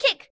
highkicktoe2.ogg